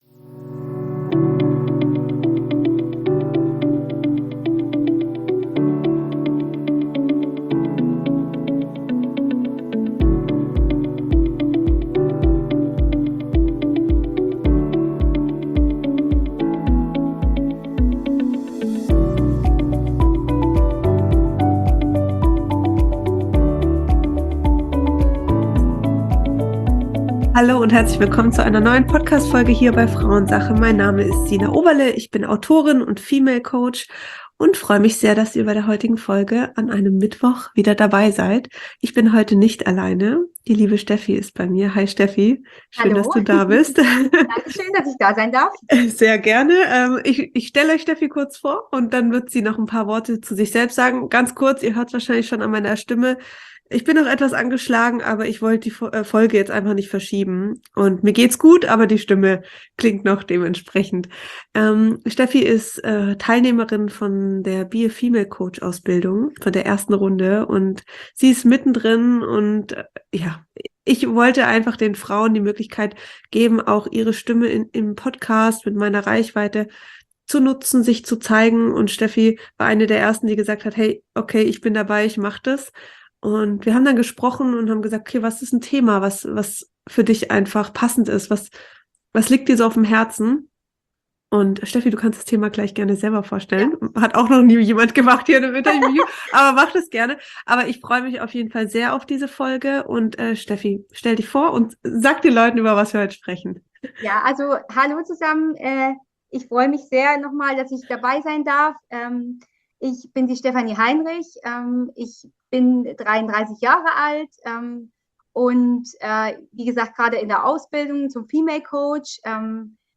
Dezentralisierung von Bildung und das Problem des Schulsystems - Im Interview